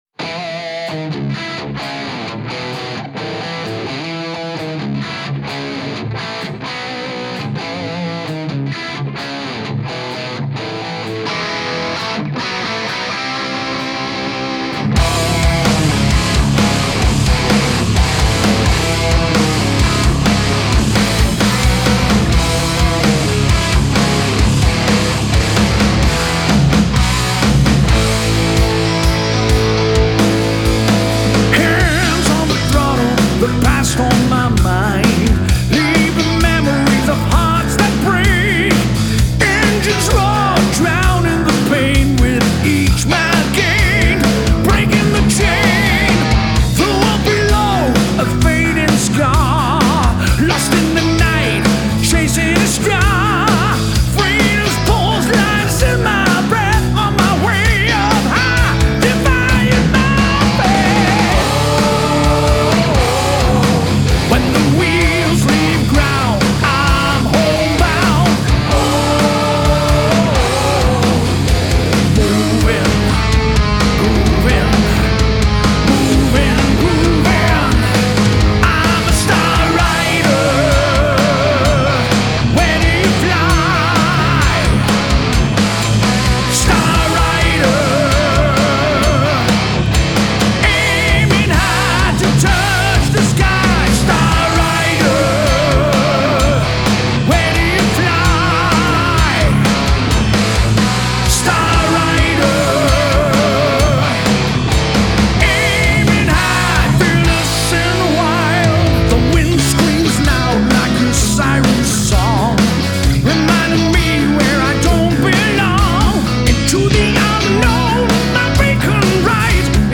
Deutsch-Niederländischer Hard Rock